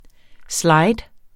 Udtale [ ˈslɑjd ]